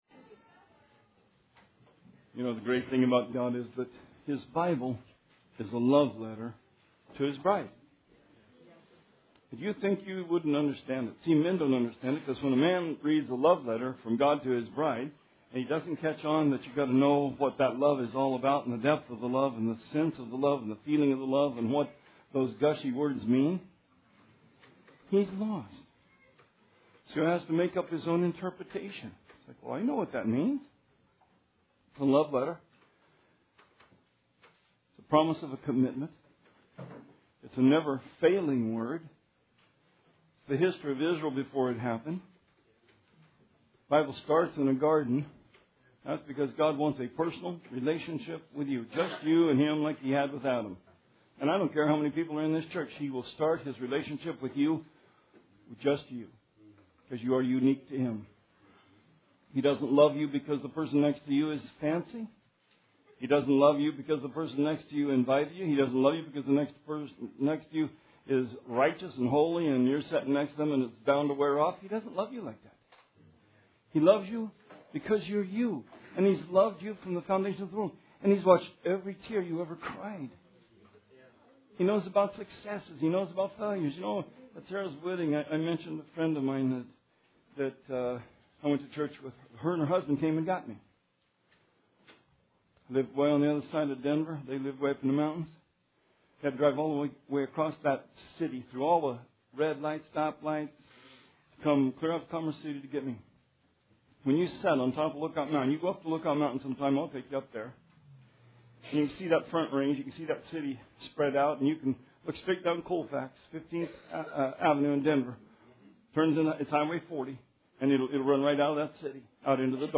Sermon 3/27/16